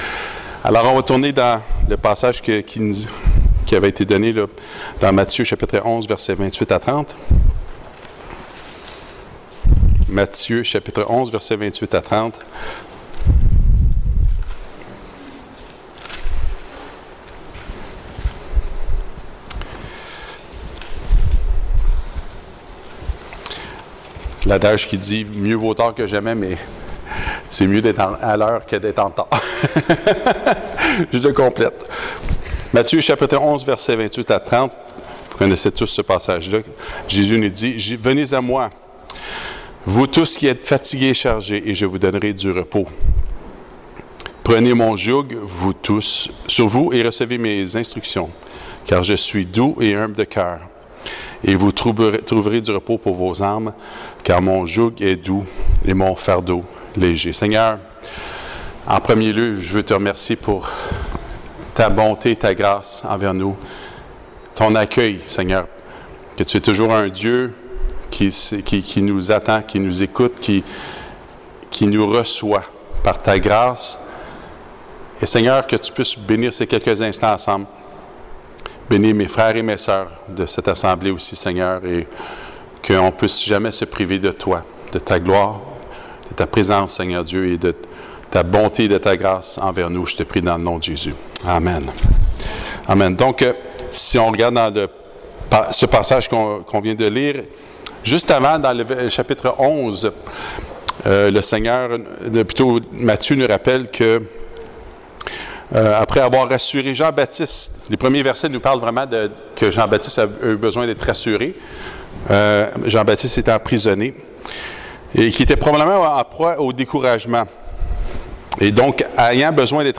Ce message a été prêché